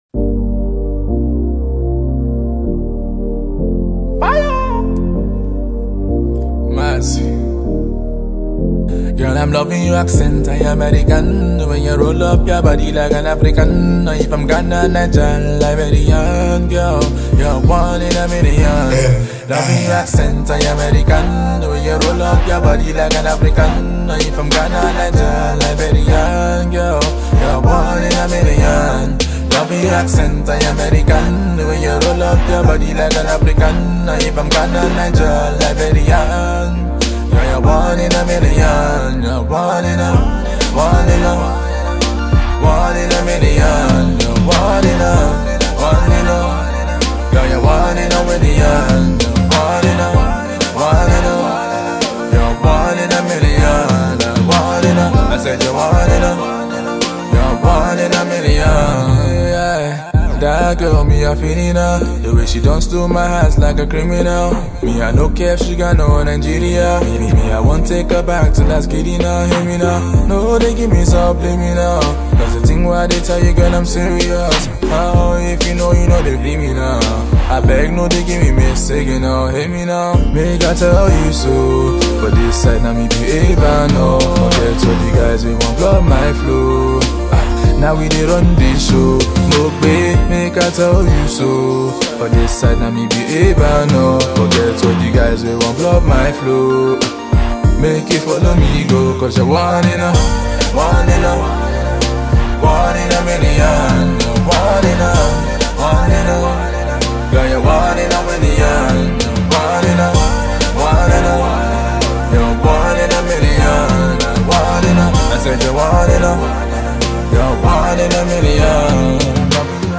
smooth mellow vocals